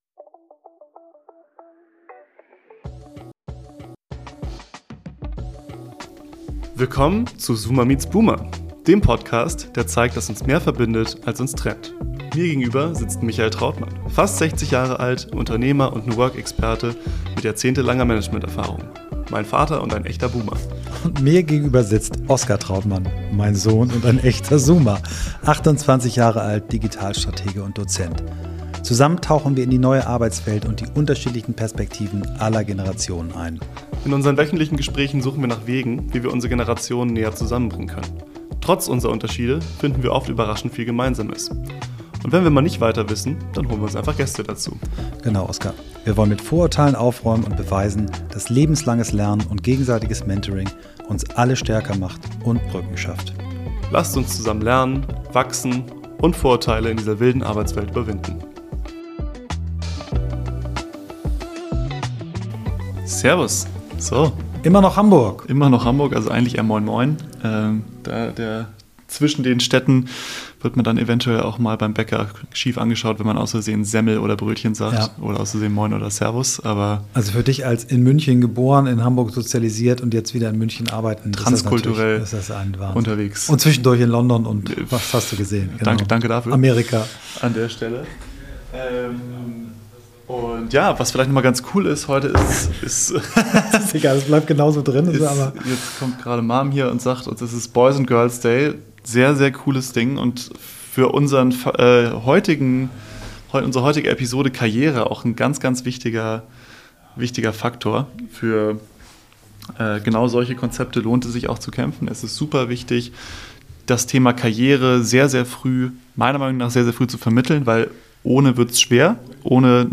In unserer aktuellen Folge, die wir in Hamburg am Girls- und Boys-Day aufgenommen haben, sprechen wir über Karriere.